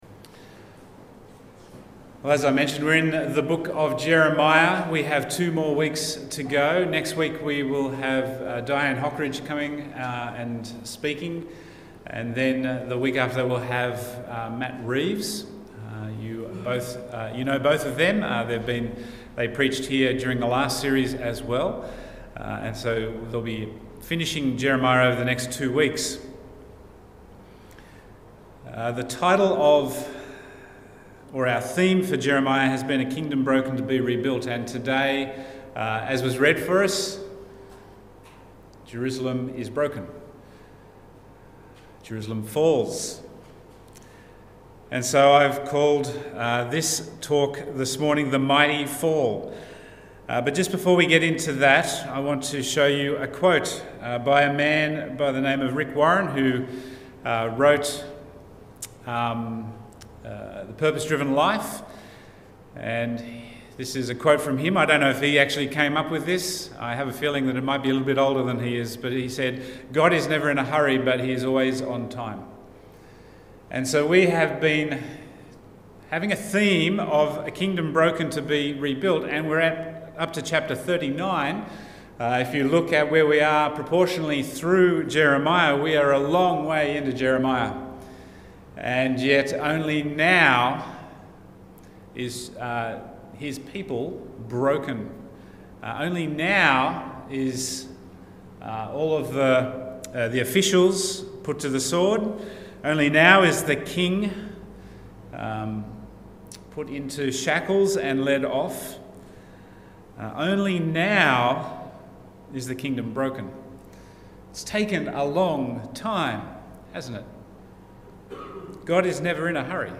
Bible Text: Jeremiah 39:1-12 | Preacher